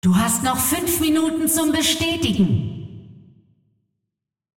vo-anncr-fem1-tournaments-lockin-5min-01.ogg